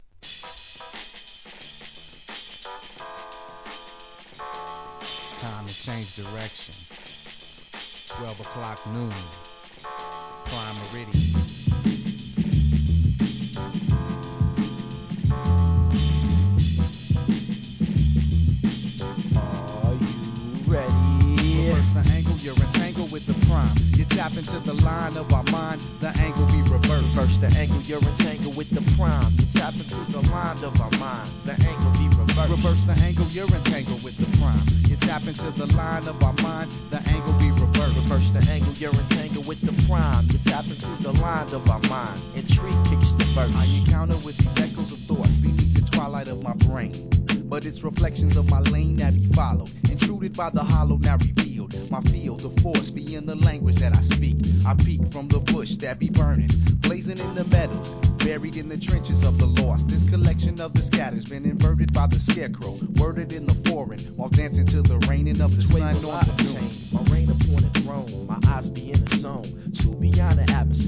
HIP HOP/R&B
スピリチュアルな極上ホーンが印象的なJAZZY HIP HOP!!